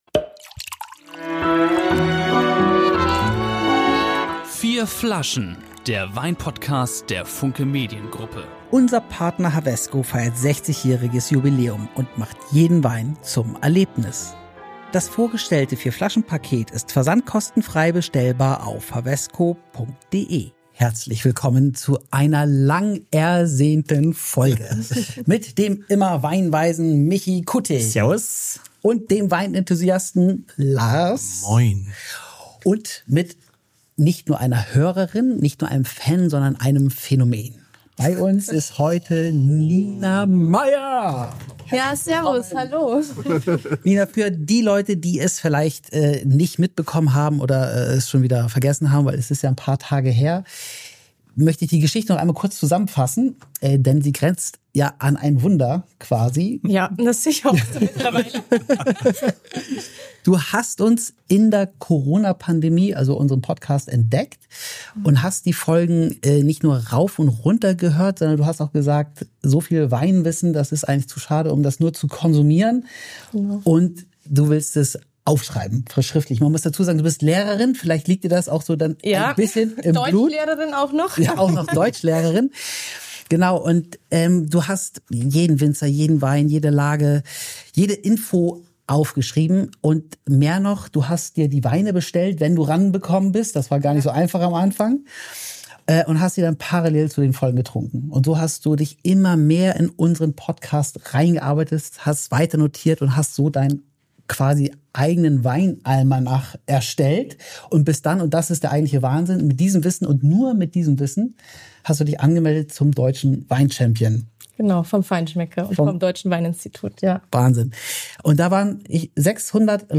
Ob Rotwein, Weißwein oder Rosé – unsere Weinexperten probieren für Dich live die besten Tropfen und teilen ihr Wissen mit dir.